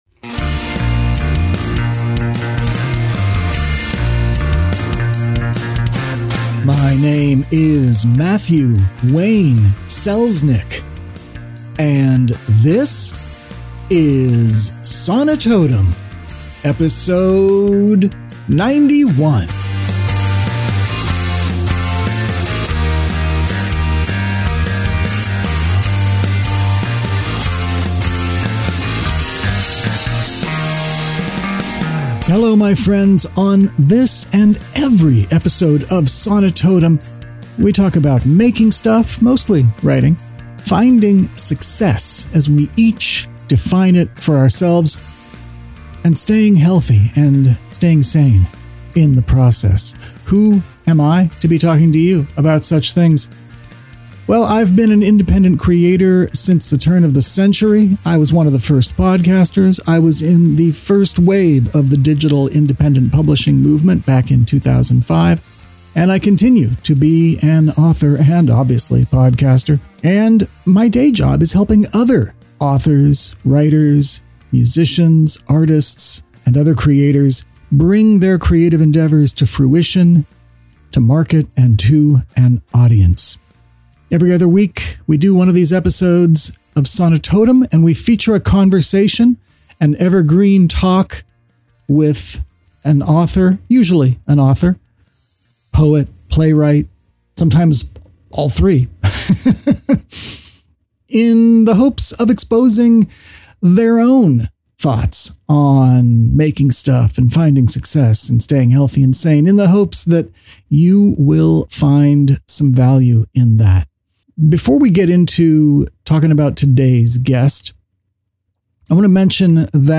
Sonitotum 091: In Conversation w